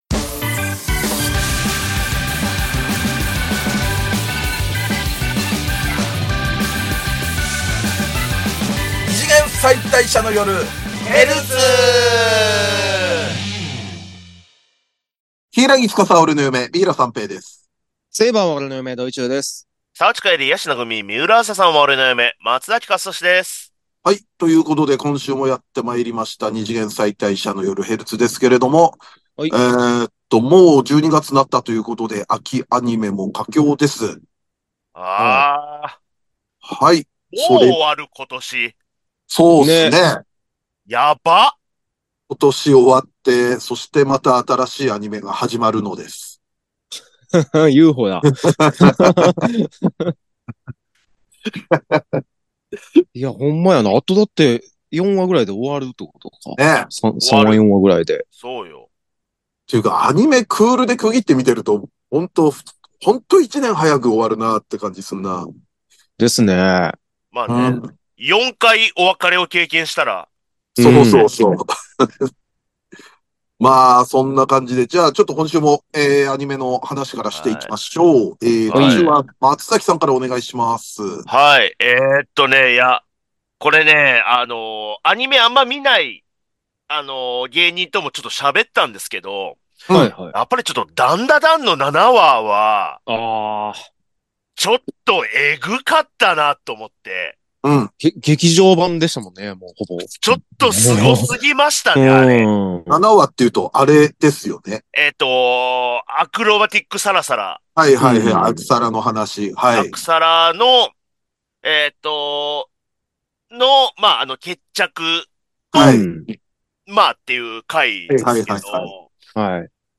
旬なテレビアニメの感想話やオススメ漫画話で楽しく陽気にバカ話！二次元キャラクターを嫁に迎えた芸人３人による、キャラ萌え中心アニメトークポッドキャストラジオです！